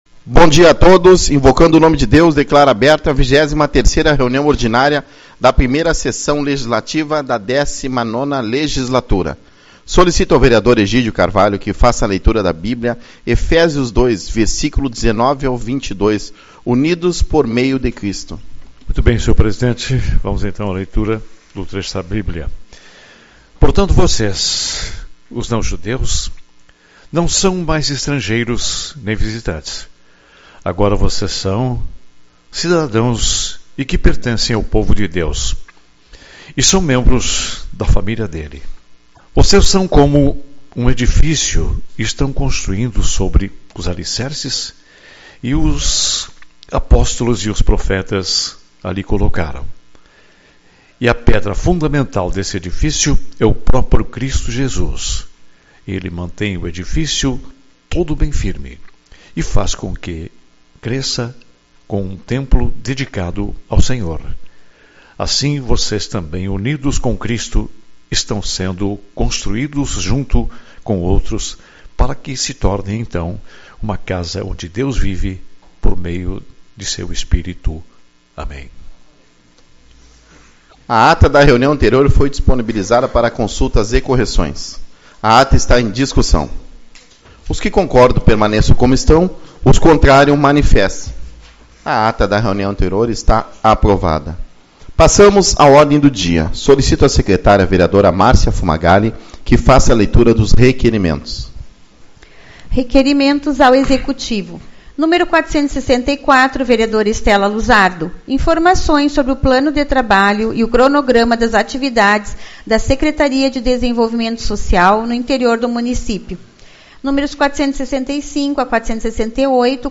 24/04 - Reunião Ordinária